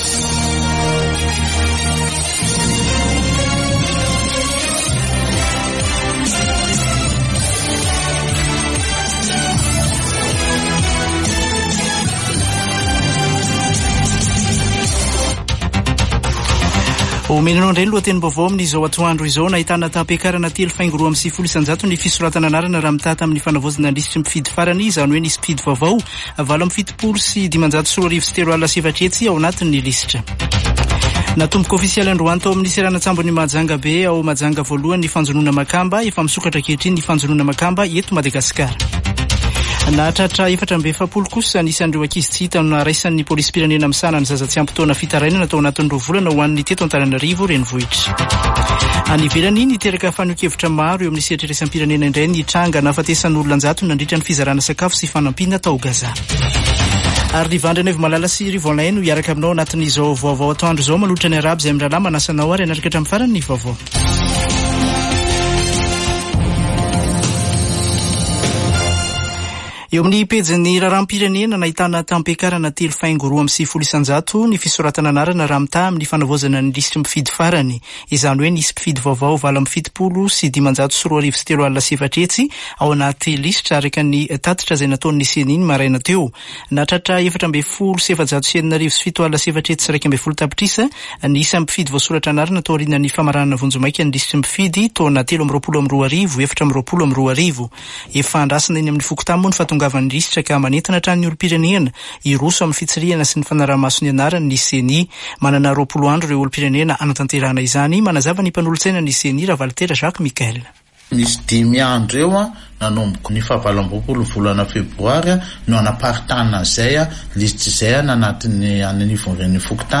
[Vaovao antoandro] Zoma 1 marsa 2024